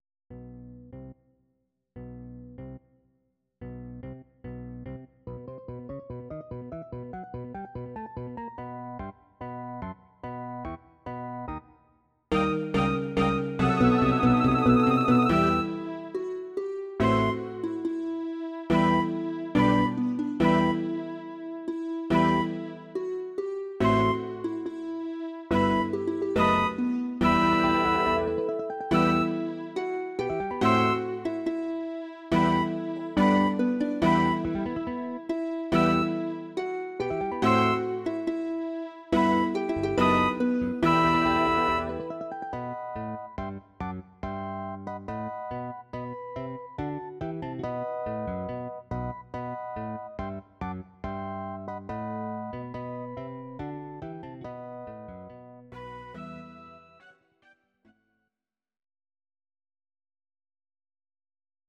These are MP3 versions of our MIDI file catalogue.
Your-Mix: Instrumental (2065)